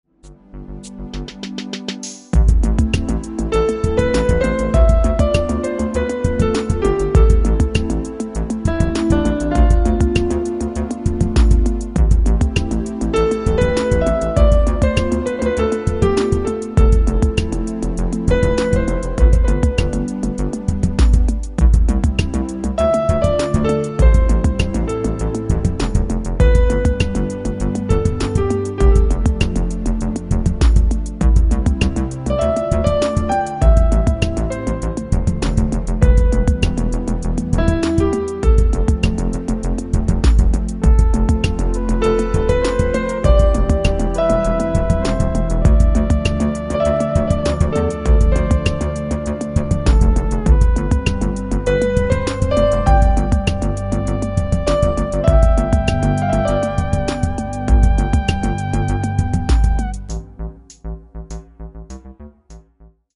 (музыкальная тема) 27 февраля 2001г.